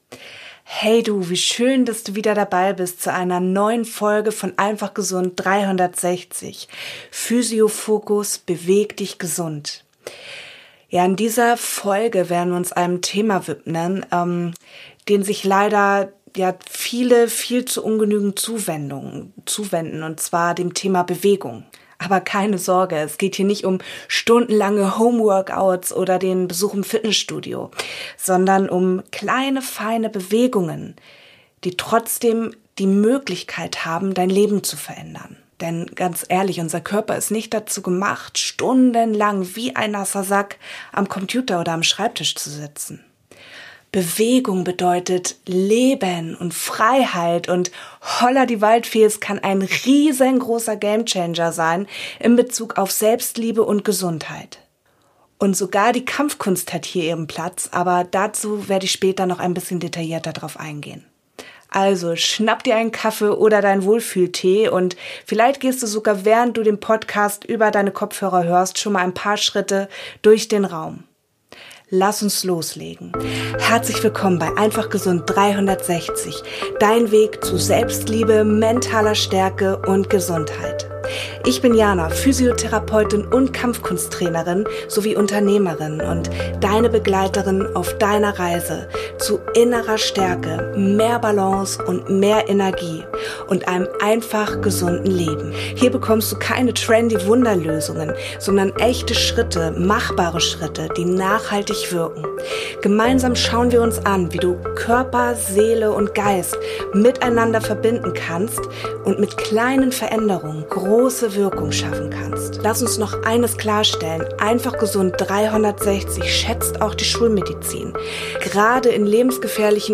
Audition Template: 24 Mono tracks routed to a Stereo Master. 44.1k, 24 bit, Stereo Master.